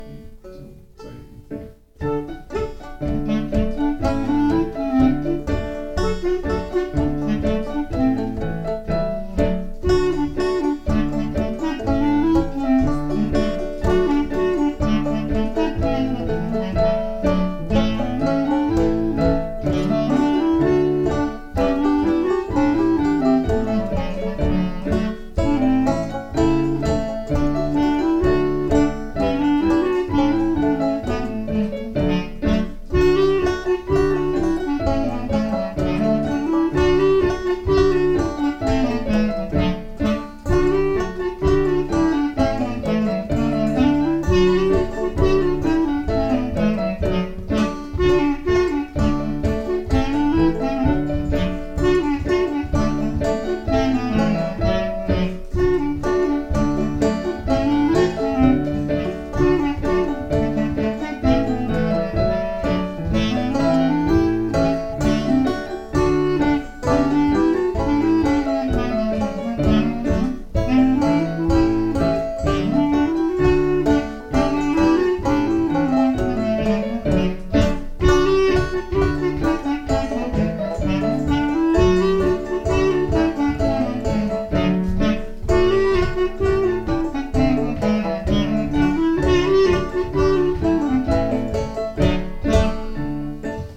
Øveaften 16. oktober 2024: